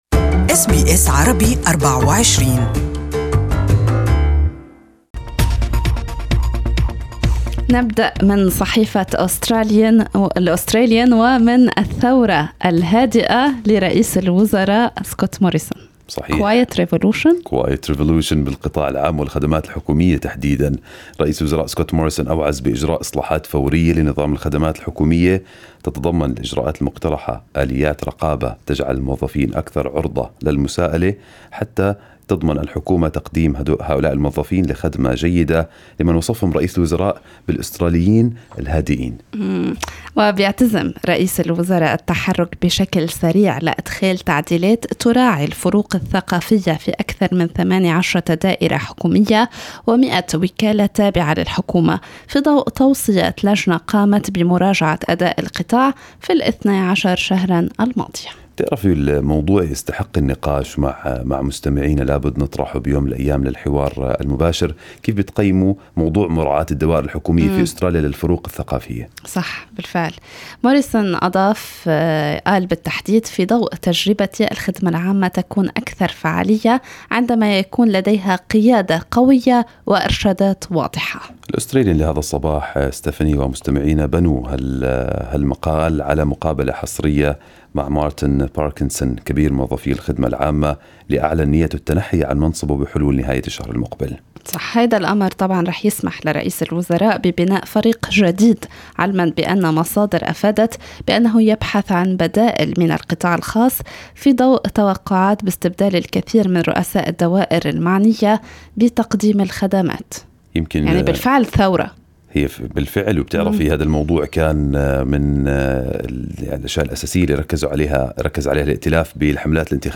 Press review: Two dead from listeria linked to smoked salmon